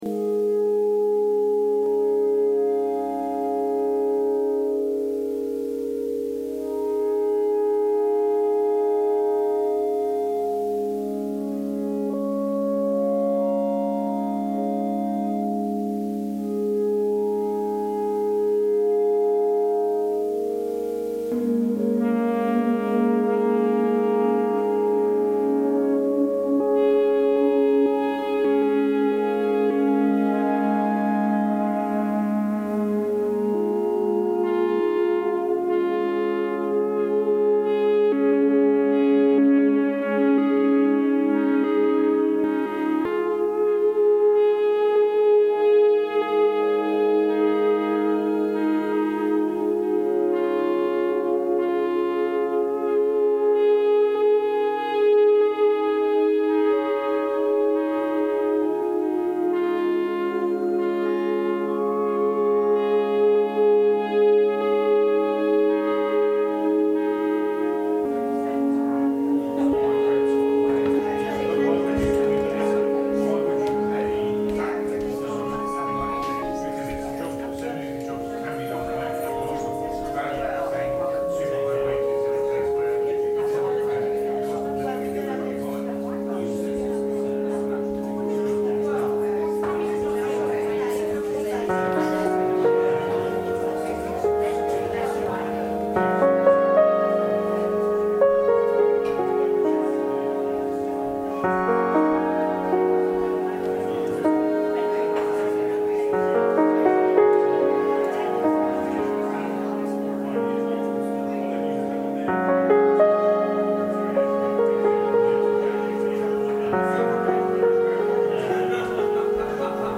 piano
synthesizer